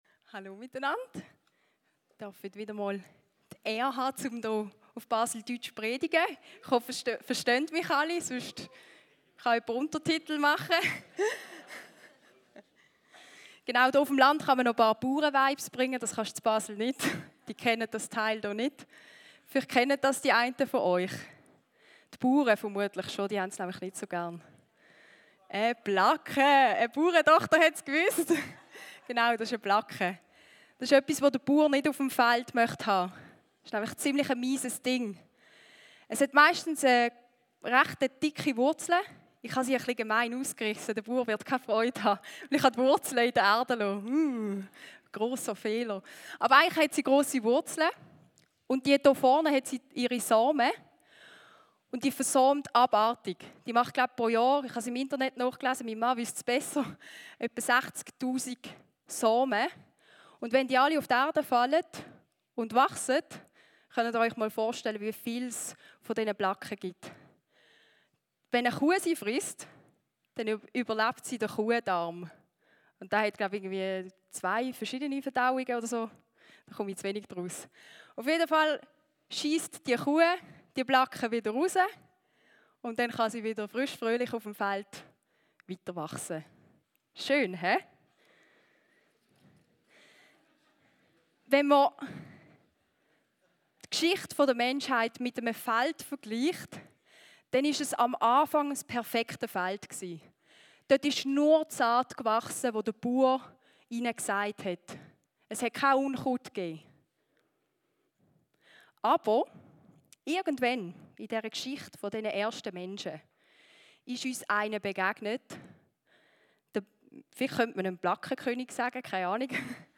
Eine predigt aus der serie "RISE & FALL." In dieser Predigt schauen wir auf einen der wohl eindrücklichsten Momente im Leben von David: Obwohl er die Gelegenheit hätte, Saul zu töten – seinen Feind, der ihn seit Jahren verfolgt –, entscheidet er sich dagegen.